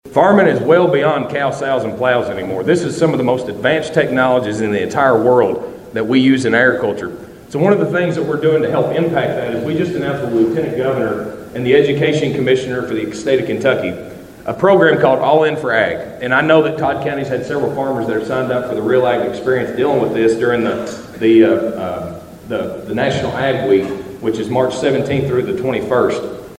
Shell was the featured speaker for the Todd County Agriculture Appreciation Breakfast at the Elkton Baptist Church Fellowship Hall Friday morning.